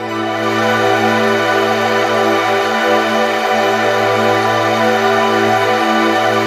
P A D39 01-L.wav